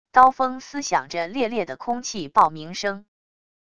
刀锋撕响着猎猎的空气爆鸣声wav音频